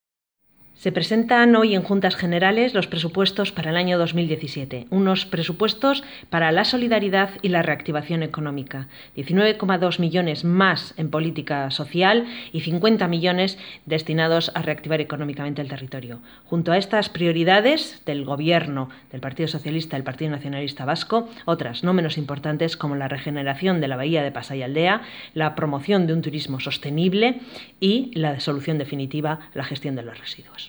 La portavoz socialista ha señalado: